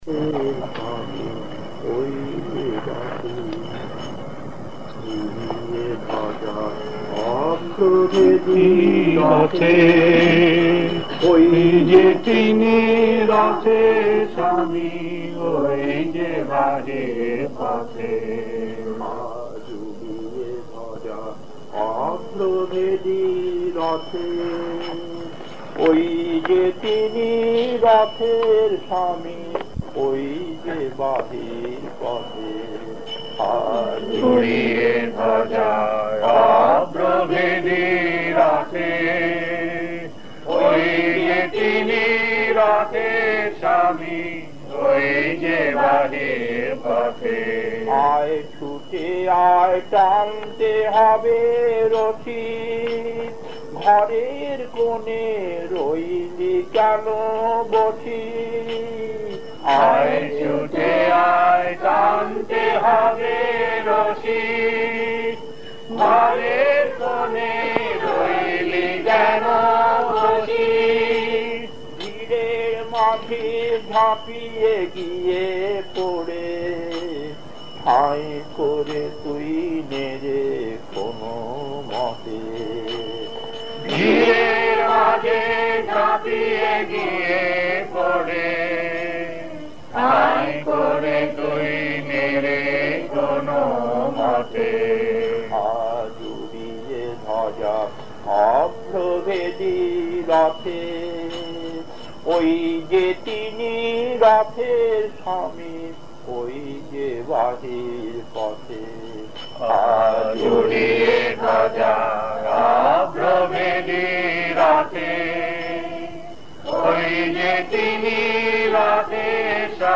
Kirtan
Puri